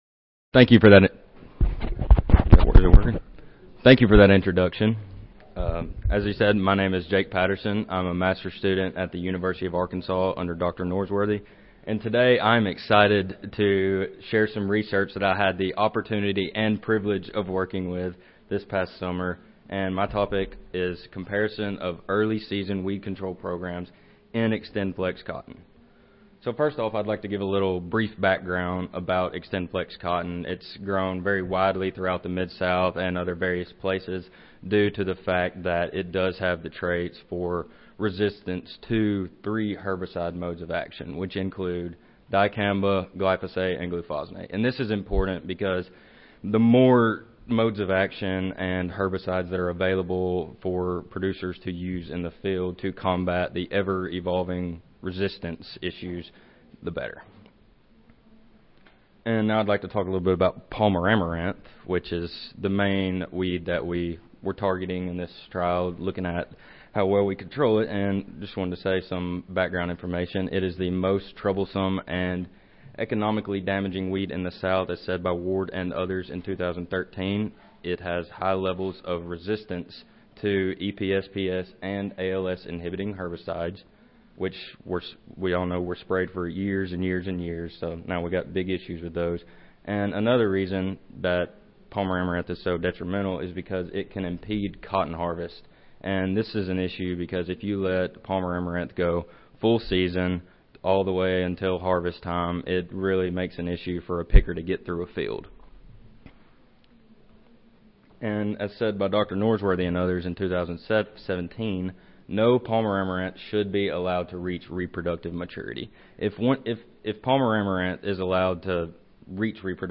Recorded Presentation Weed Science research comparing early season weed control programs in Xtendflex cotton.